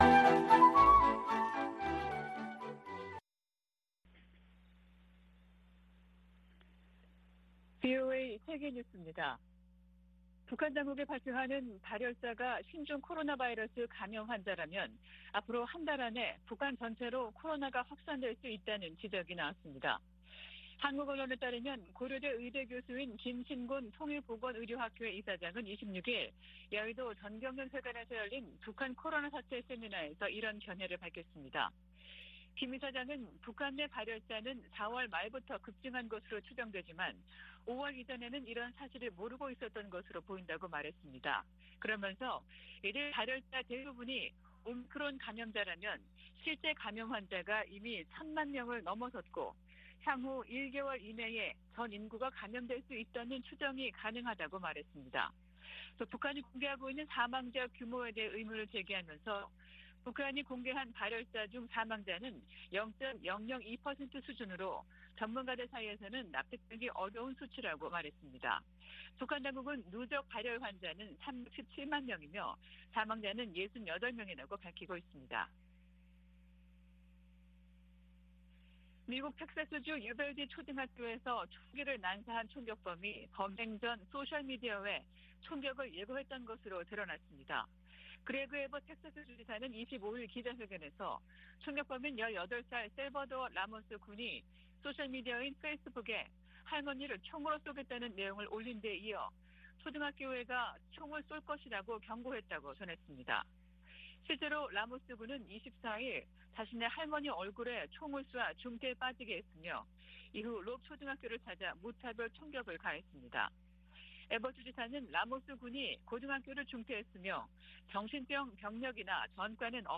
VOA 한국어 아침 뉴스 프로그램 '워싱턴 뉴스 광장' 2022년 5월 27일 방송입니다. 미 국무부 고위 관리가 북한의 탄도미사일 발사를 강력히 규탄하면서도 인도적 지원을 여전히 지지한다는 입장을 밝혔습니다. 유엔은 북한의 탄도미사일 발사가 긴장만 고조시킨다며 완전한 비핵화를 위한 외교적 관여를 촉구했습니다. 유엔 안보리가 새 대북 결의안을 표결에 부칩니다.